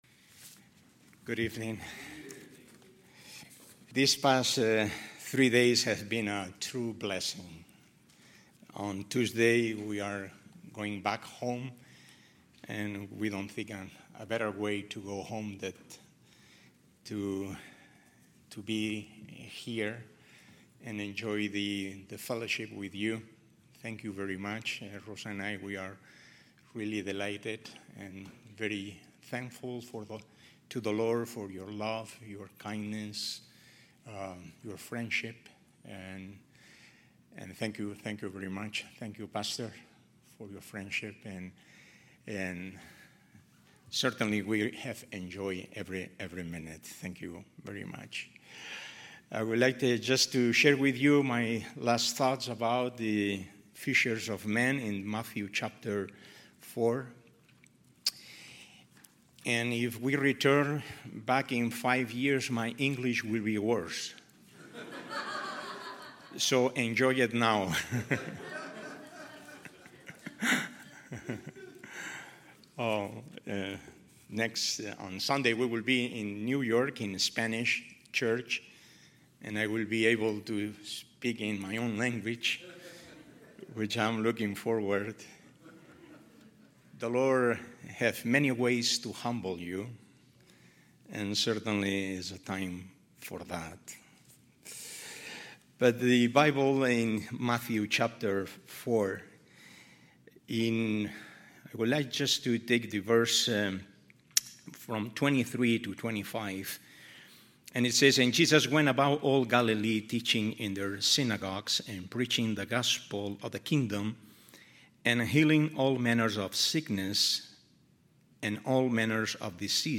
2025 Missions Conference , Sermons